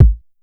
MZ Kick [Southside].wav